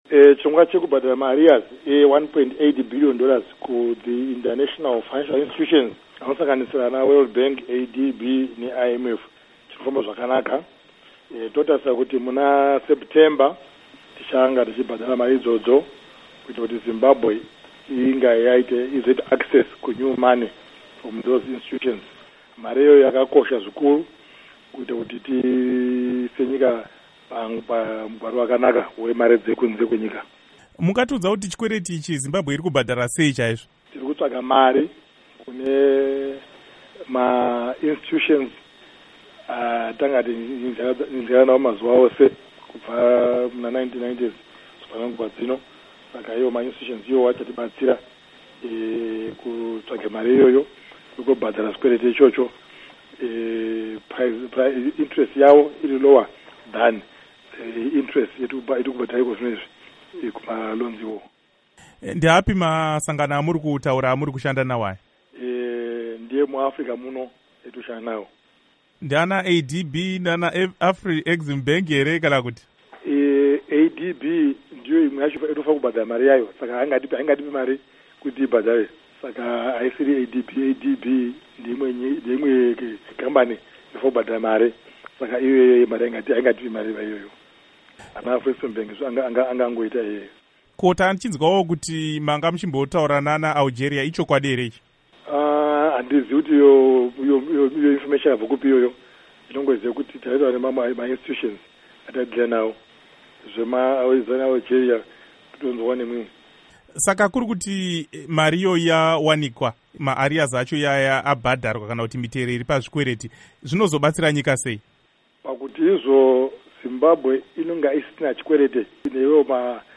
Hurukuro naDr John Mangudya